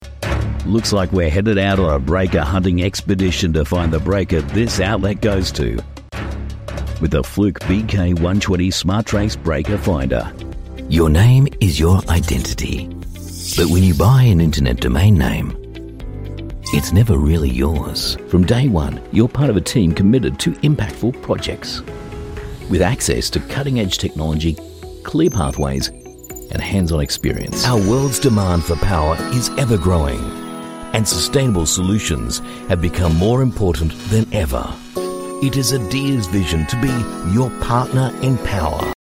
Doubleur anglais (australien)
Vidéos explicatives